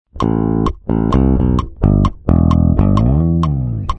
🔊Variación👇🏻
Esta es, la segunda casilla que aquí mostramos, tienes un arrastre o glissando hacia el traste 12, lo cual, rompe la monotonía y empuja la linea de bajo mucho mas, y a parte es muy divertido tocarlo.
Feel-Good-Inc-riff-2-variacion.mp3